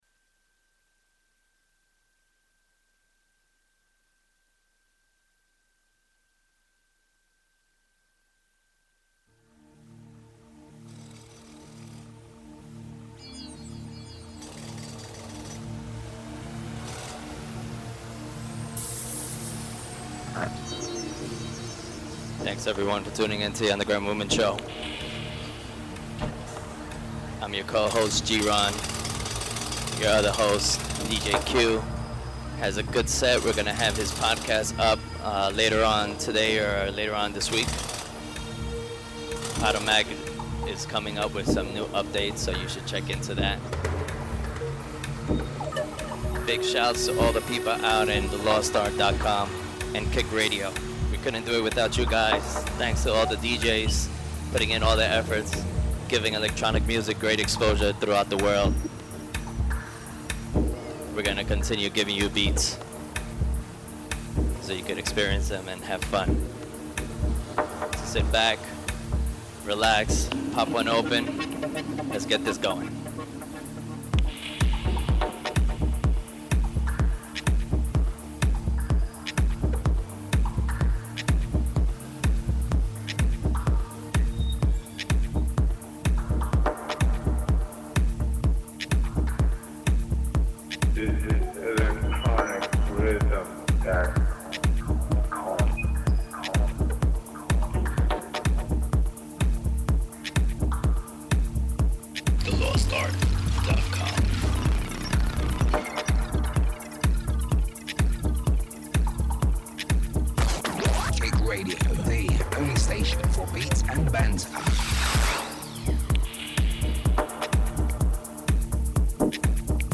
dark techno funk
an entrancing track filled with engulfing sounds